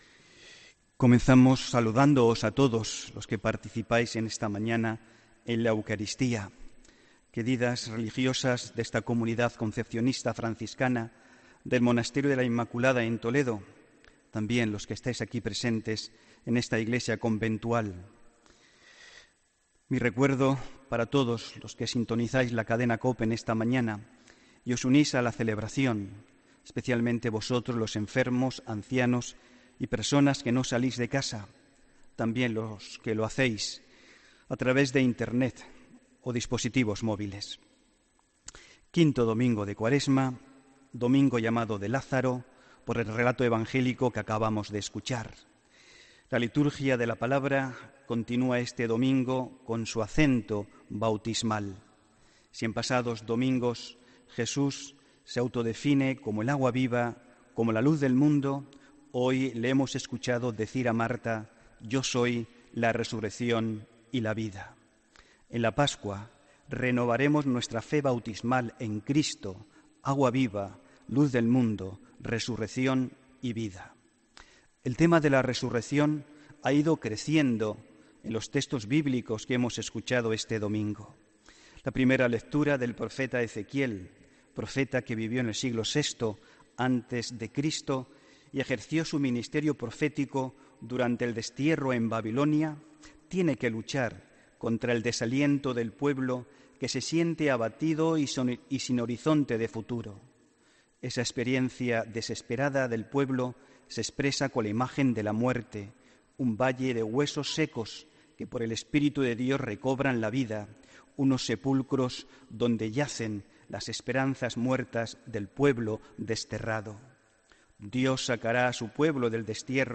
Homilía del domingo 2 de abril de 2017